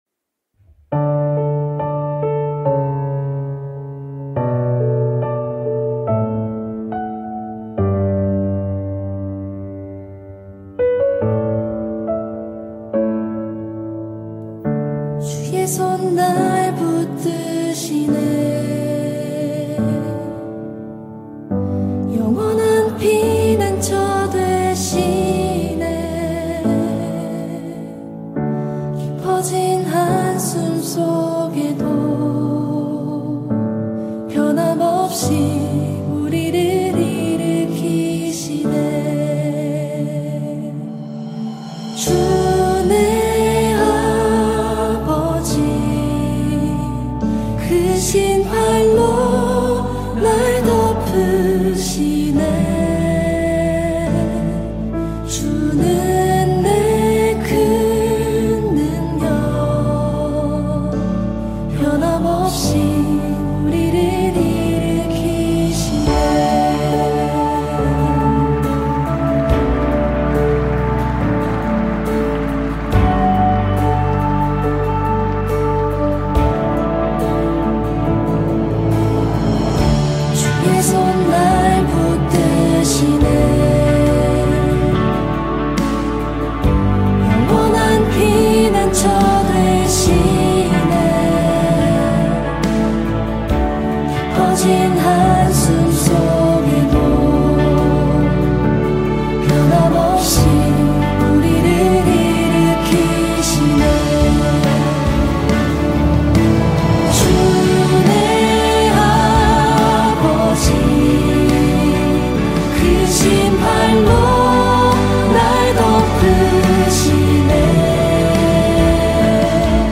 [찬양]